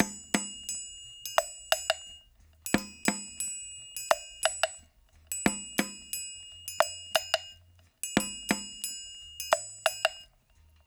88-PERC4.wav